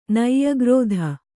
♪ naiyagrōdha